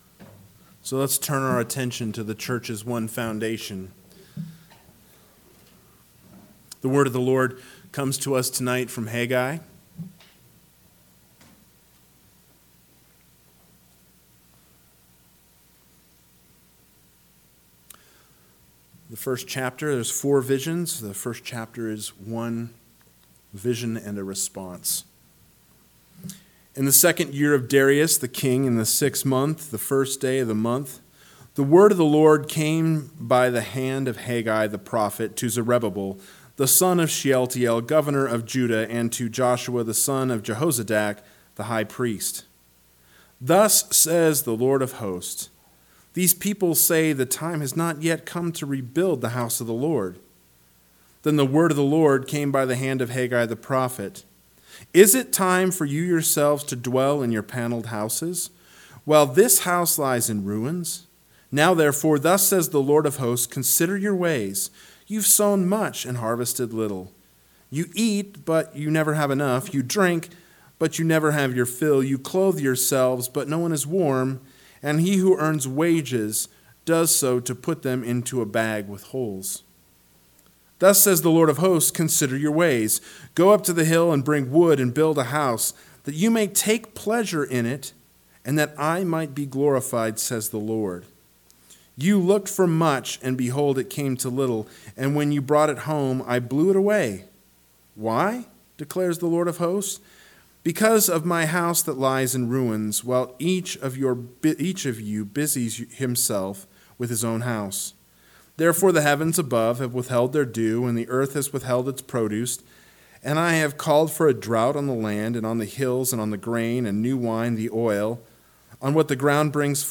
PM Sermon – 7/10/2022 – Haggai 1:1-15 – What Are We Building?